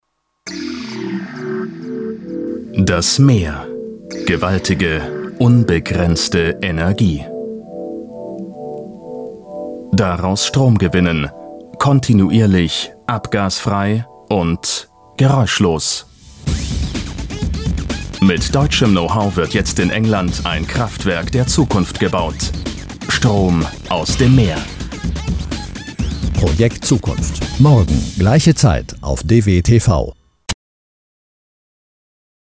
deutscher Sprecher
Kein Dialekt
Sprechprobe: Industrie (Muttersprache):
german voice over artist